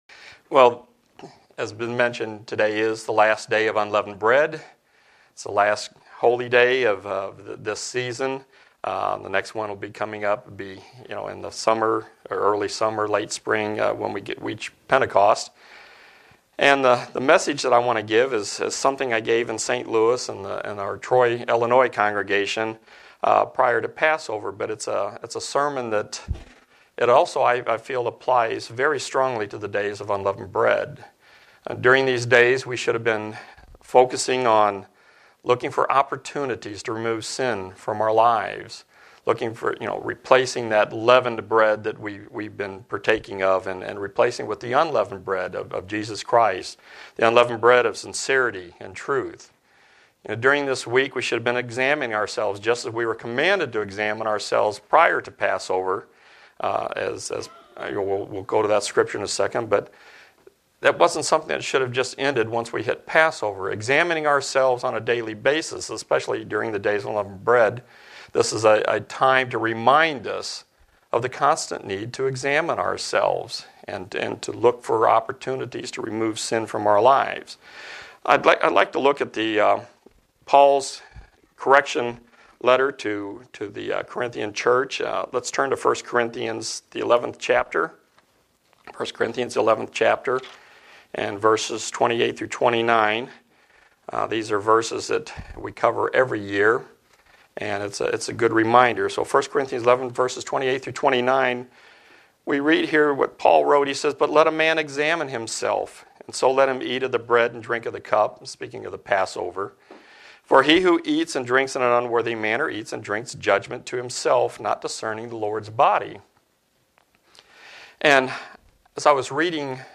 Given in Buffalo, NY Elmira, NY
Print Make sure we have this true sense in our lives from day to day. sermon Studying the bible?